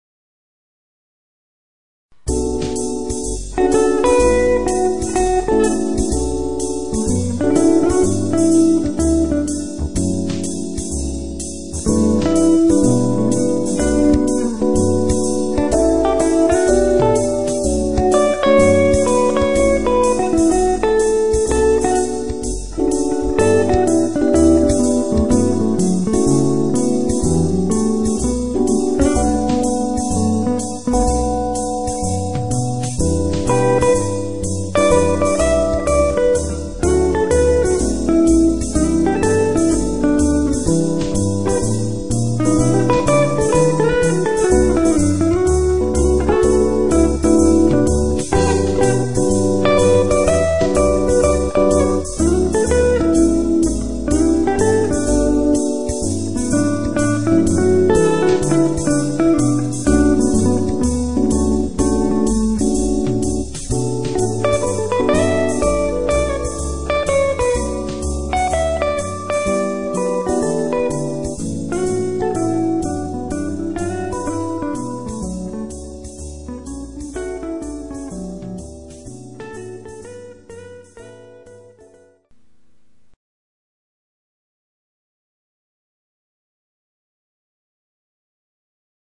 A lounge album with synthesised backings.
Nice guitar tone from my Gibson 335 on this one.